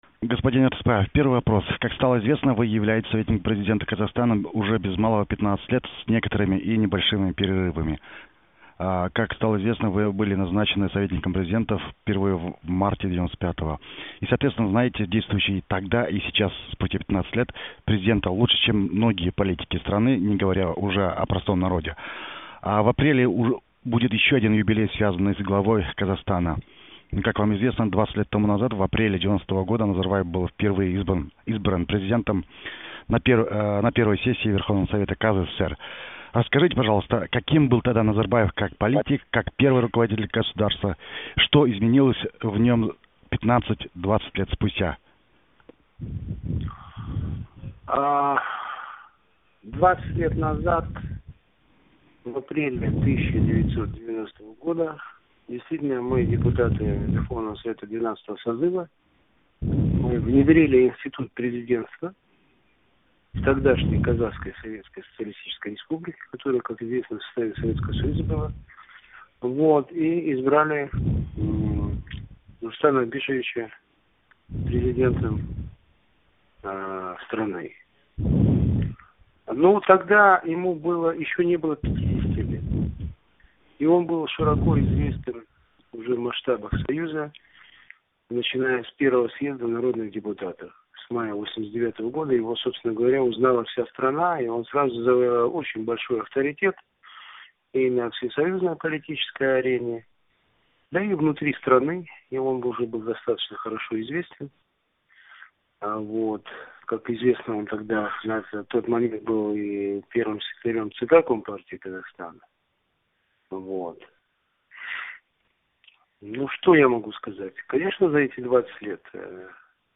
Запись интервью с Ертысбаевым